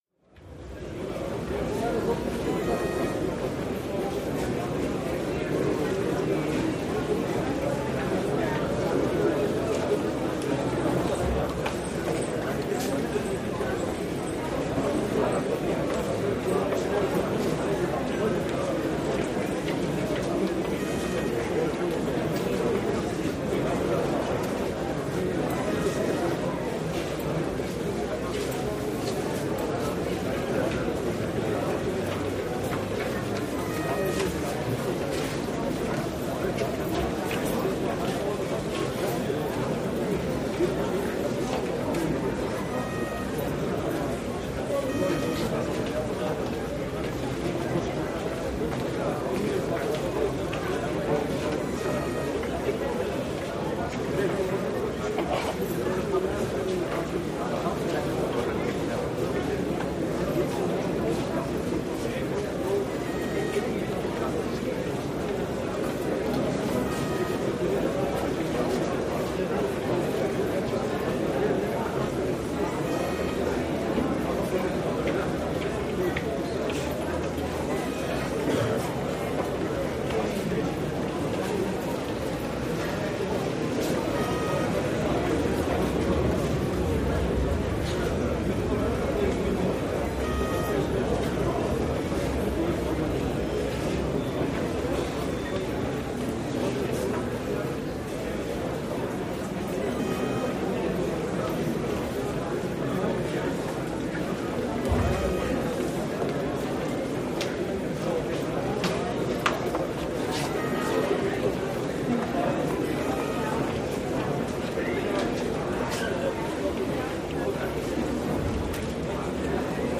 State Institution Ambience